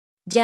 j 44
Phoneme_(Commonscript)_(Accent_0)_(44)_(Female).mp3